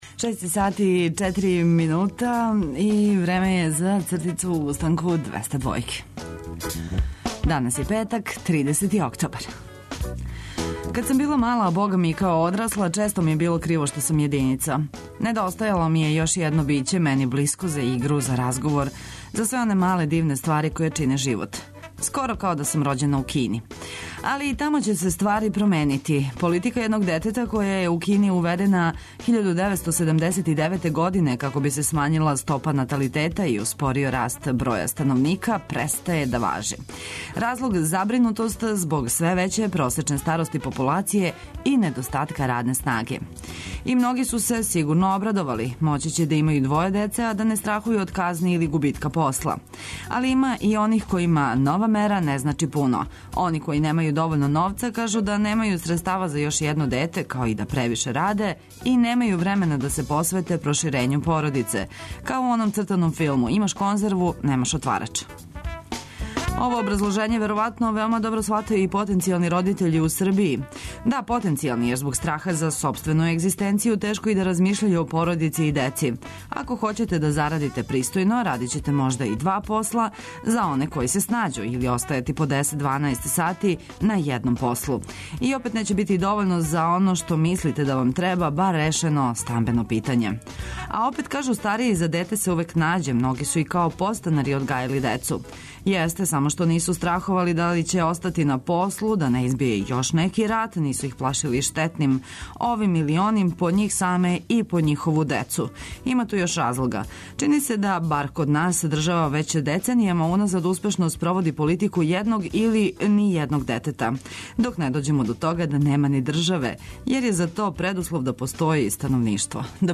Ведро расположење вас и овог јутра чека на таласима Београда 202, уз добру музику и праву дозу дневних информација.